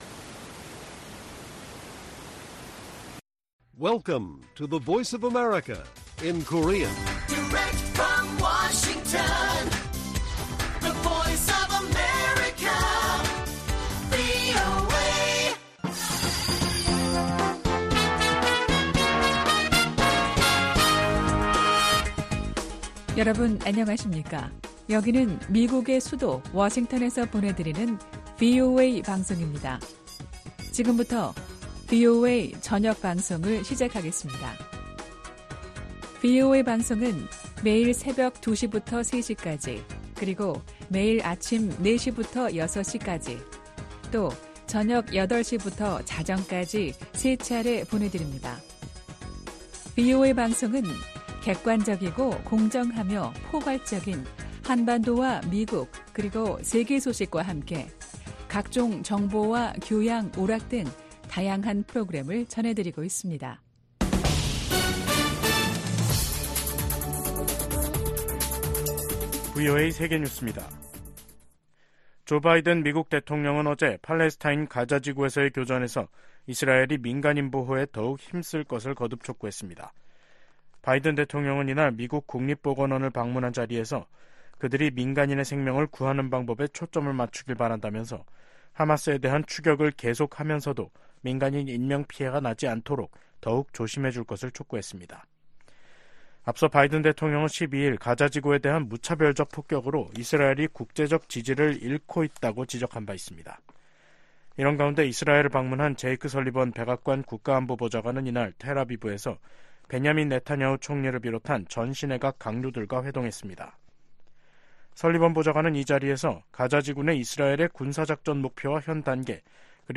VOA 한국어 간판 뉴스 프로그램 '뉴스 투데이', 2023년 12월 15일 1부 방송입니다. 내년도 회계연도 미국 국방 정책의 방향과 예산을 설정한 국방수권법안이 의회를 통과했습니다. 미국 정부가 한반도 완전한 비핵화 목표에 변함 없다고 확인했습니다. 영국 상원이 북한의 불법적인 무기 개발과 북러 간 무기 거래, 심각한 인권 문제 등을 제기하며 정부의 대응을 촉구했습니다.